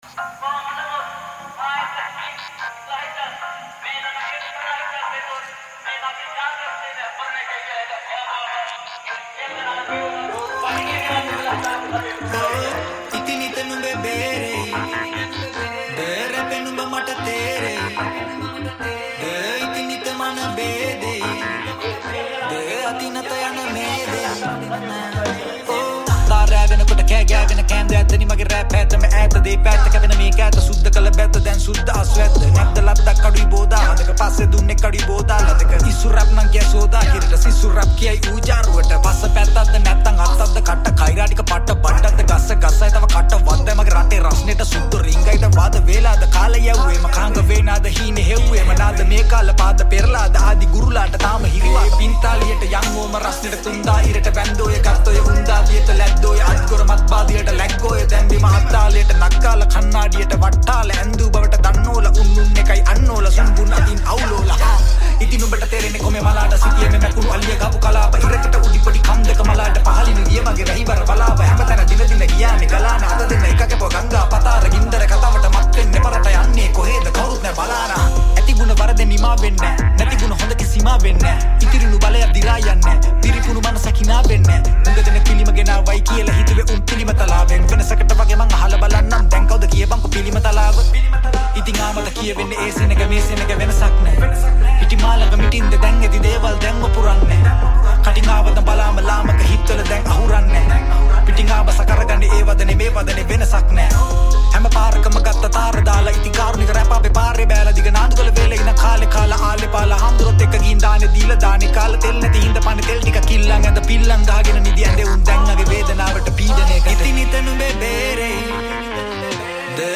High quality Sri Lankan remix MP3 (3.6).
Rap